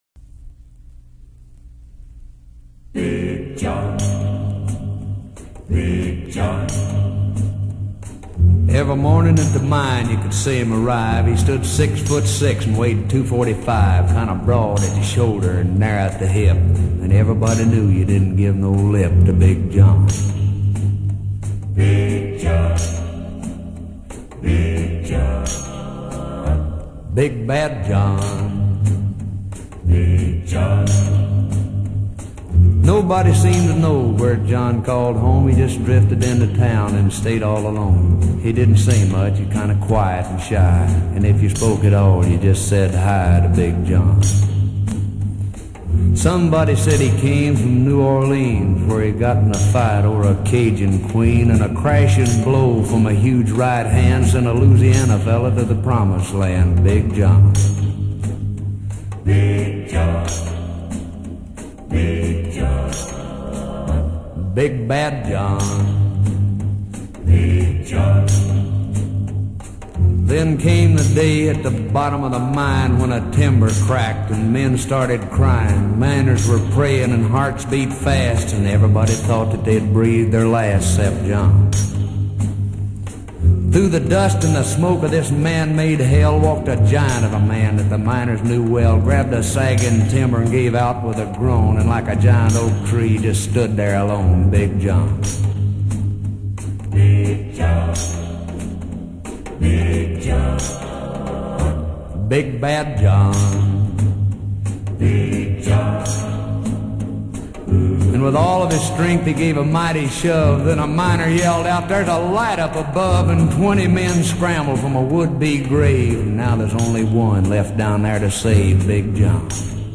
Cette ballade